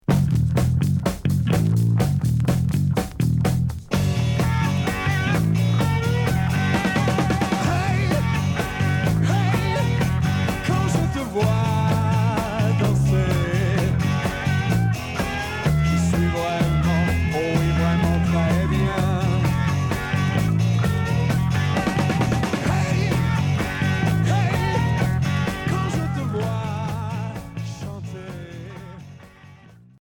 Pop Rock Quatorzième 45t retour à l'accueil